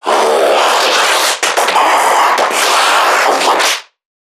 NPC_Creatures_Vocalisations_Infected [44].wav